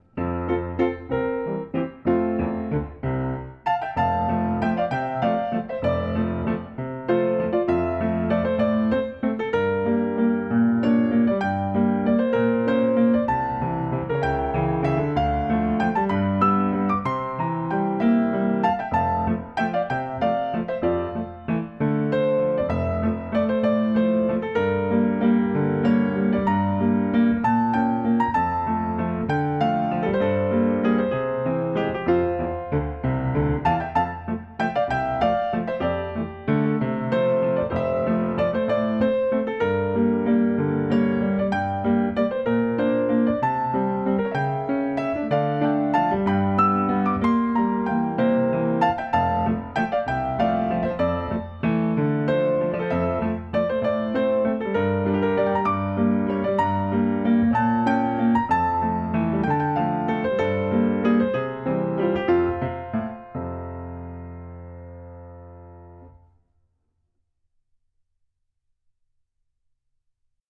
Piano accompaniment
Tempo 65
Rhythm Moderato Waltz
Meter 3/4